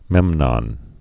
(mĕmnŏn)